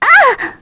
Aisha-Vox_Damage_kr_02.wav